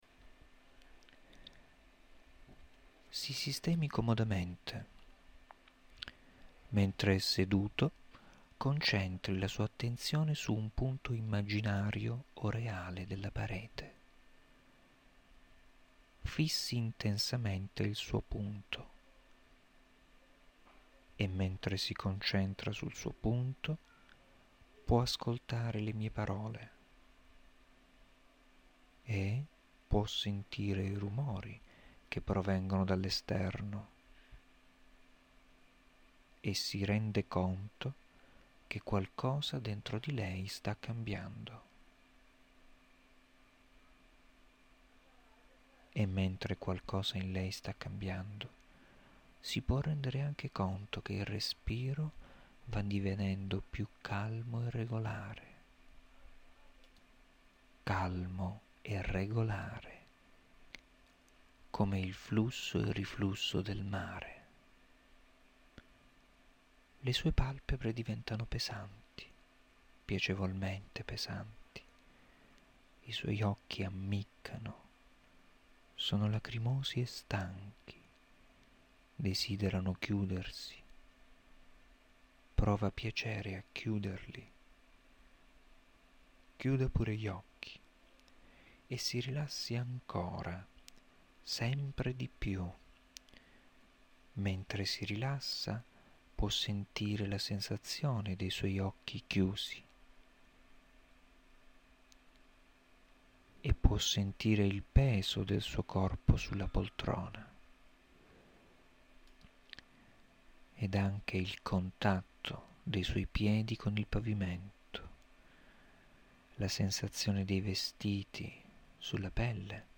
Esercizi di rilassamento
in questa area troverà alcuni esercizi di rilassamento da me registrati, comprendenti una induzione iniziale, una suggestione ed una visualizzazione (fantasia guidata).
• Terzo esempio di induzione, Guscio.